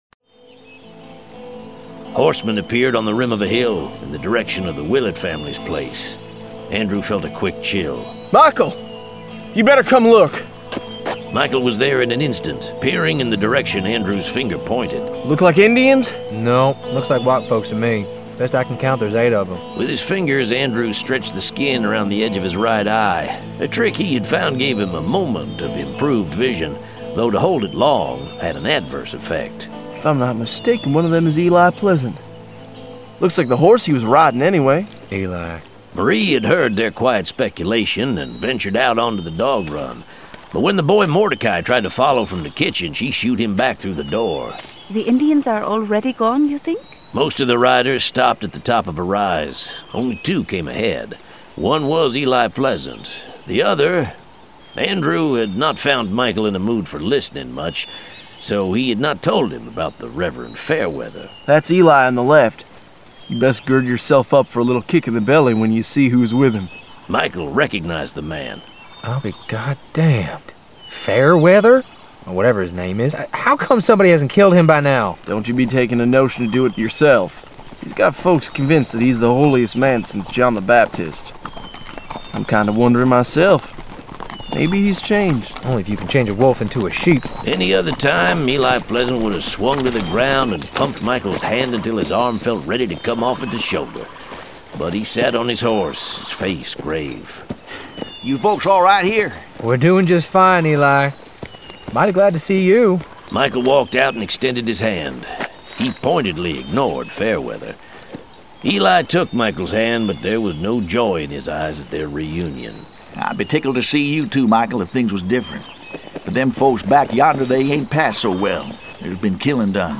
Full Cast. Cinematic Music. Sound Effects.
[Dramatized Adaptation]
Genre: Western
FLAC 5.1 SURROUND (16 bit / 44.1 khz) - Download the zip file and Play with 5.1 Surround Sound Home Theater systems or other high quality surround sound audio software.